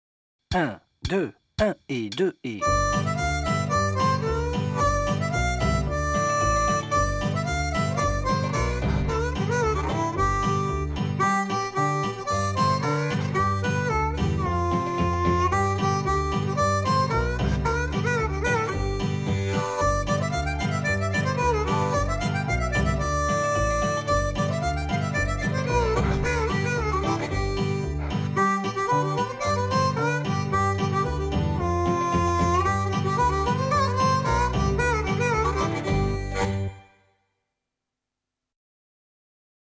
Voilà quelques jours que je potasse ce classique du Bluegrass.